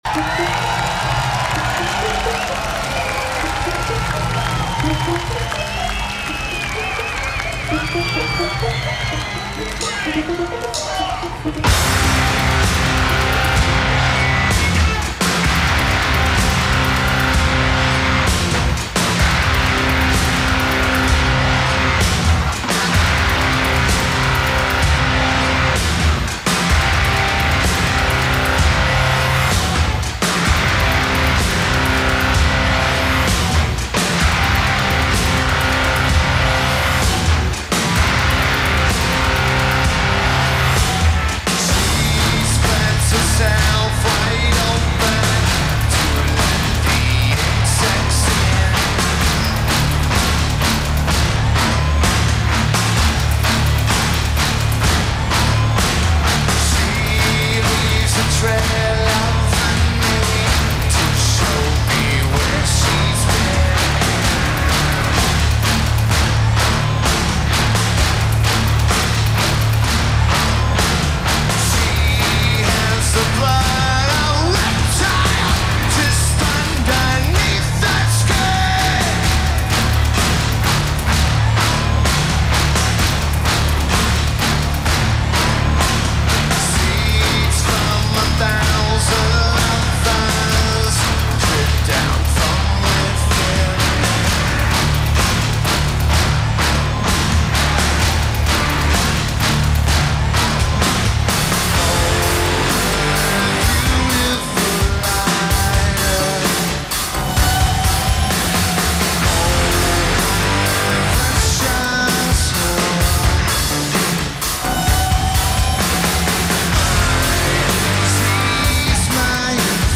Forestglade Festival
Notes: Soundboard recording from FM broadcast very good.